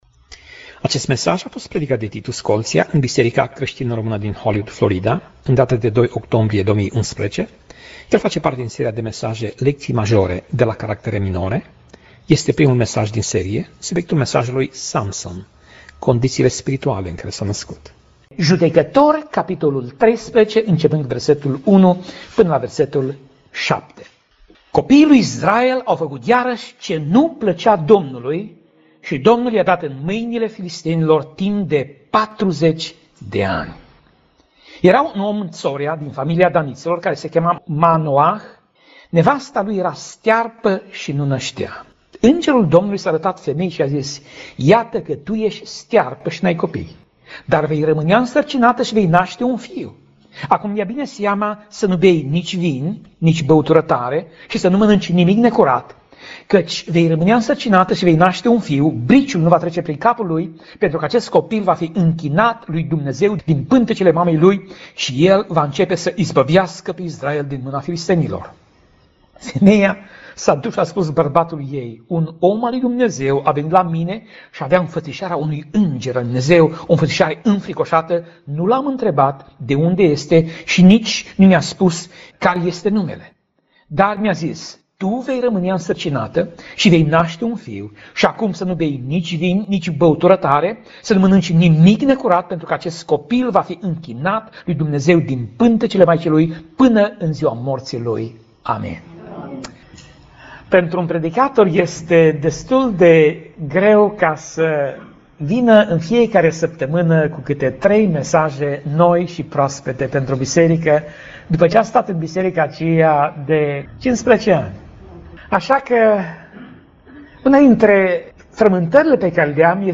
Tip Mesaj: Predica Serie: Lectii Majore de la Caractere Minore